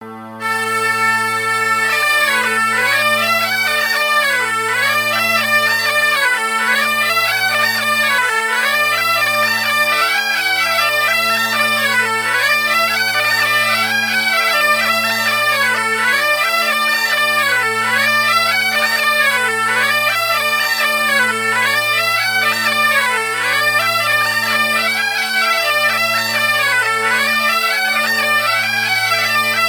Couplets à danser
Marais Breton Vendéen
danse : branle
Pièce musicale éditée